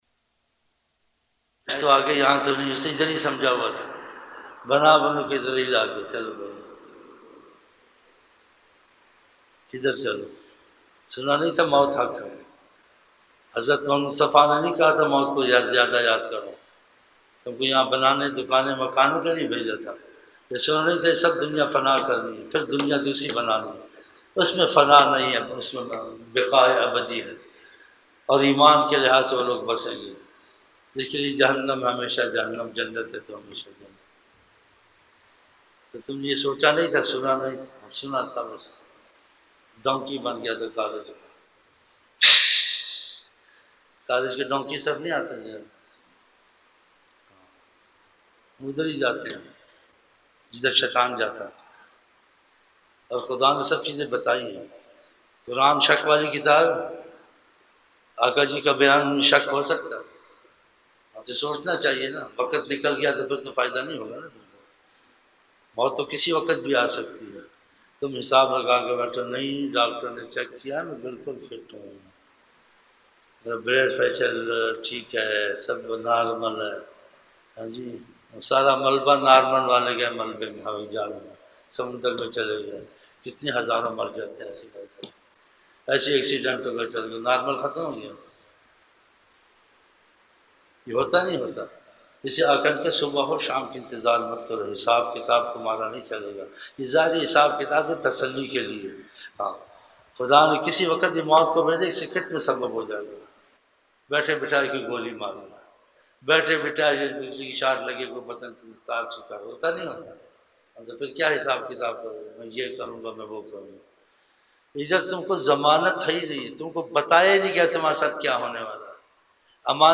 ظہر شروع کی محفل